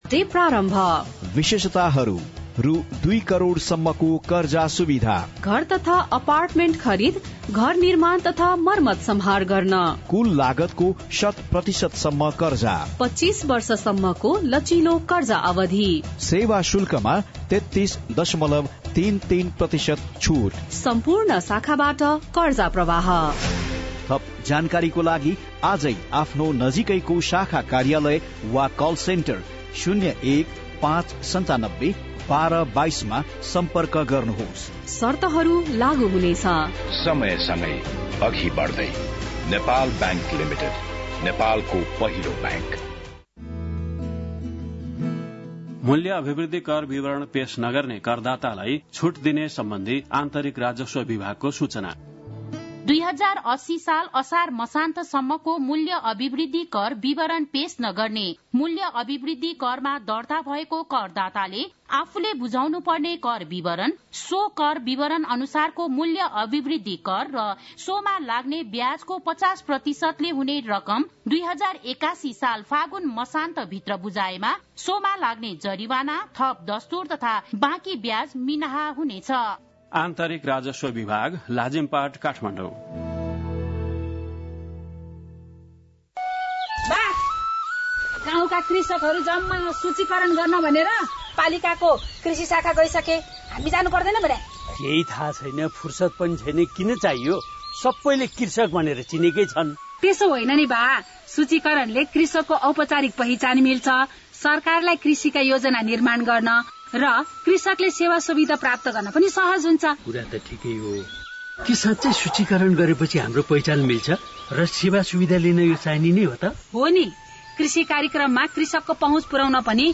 बिहान १० बजेको नेपाली समाचार : २८ पुष , २०८१